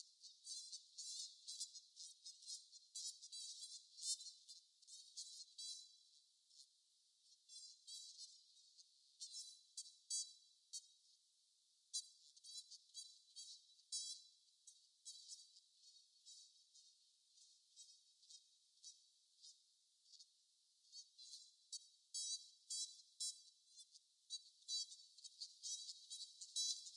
描述：在我的大学池塘上的H4N记录鹅
Tag: 现场录音 所大学泰